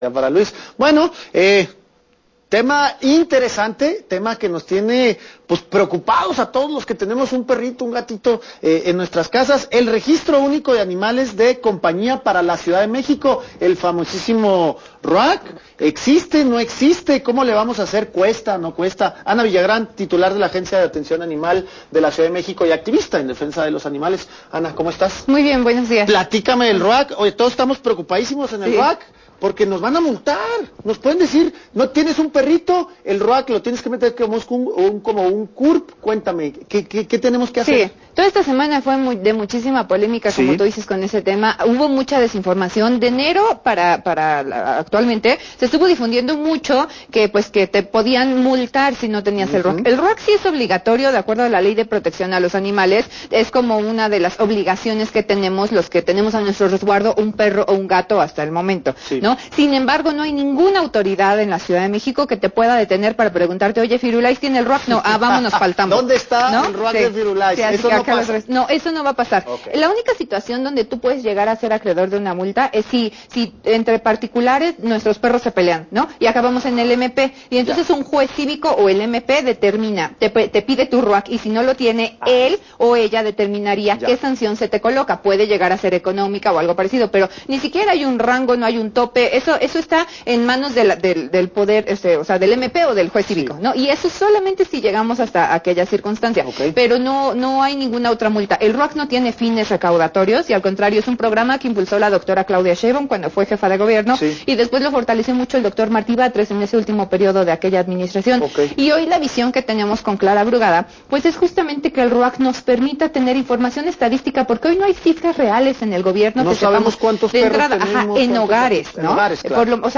Registro Único de Animales de Compañía, entrevista a Ana Villagrán